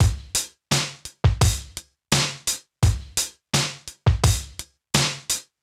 Index of /musicradar/sampled-funk-soul-samples/85bpm/Beats
SSF_DrumsProc2_85-01.wav